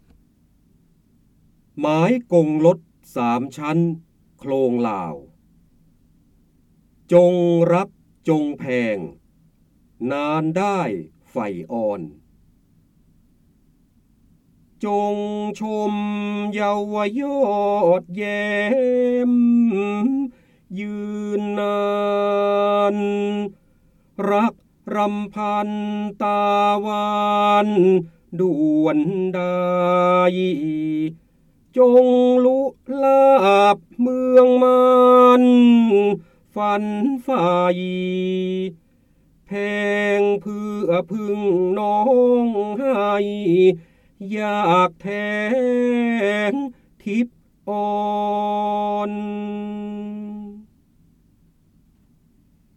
เสียงบรรยายจากหนังสือ จินดามณี (พระโหราธิบดี) หมายกงรถสามชั้นโคลงลาว
คำสำคัญ : การอ่านออกเสียง, จินดามณี, พระเจ้าบรมโกศ, พระโหราธิบดี, ร้อยกรอง, ร้อยแก้ว